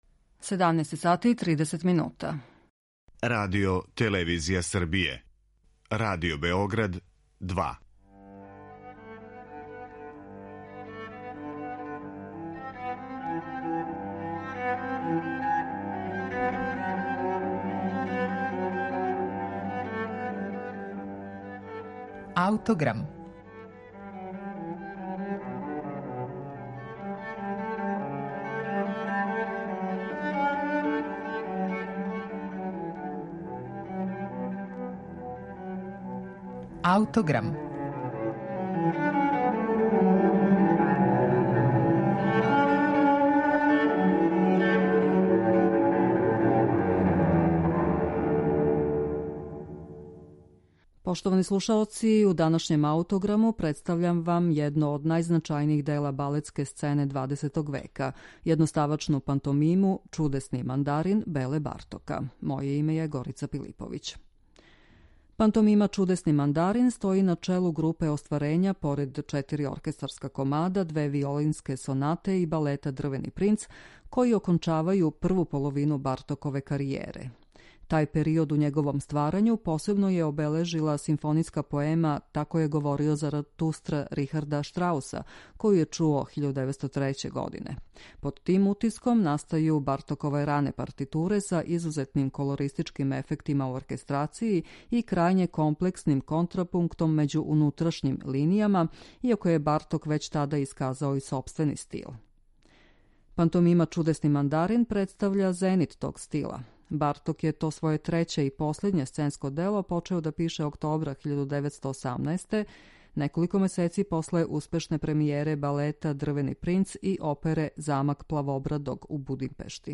Музику за овај балет можете слушати у данашњем Аутограму